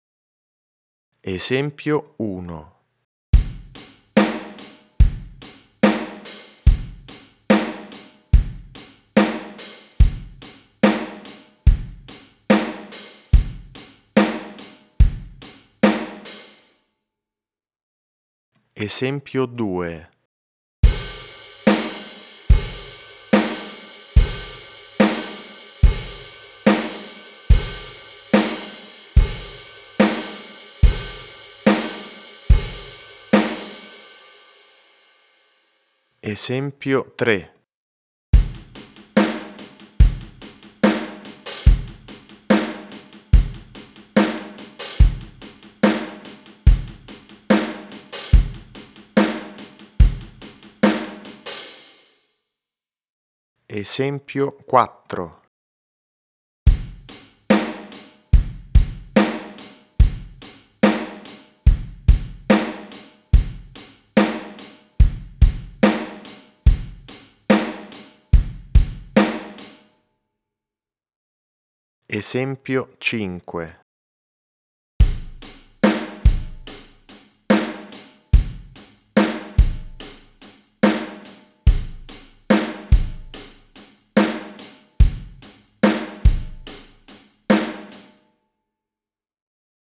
FILE MP3 – La batteria